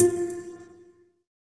SYN_Pizz6.wav